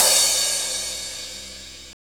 Index of /90_sSampleCDs/Roland - Rhythm Section/CYM_Crashes 1/CYM_Crash menu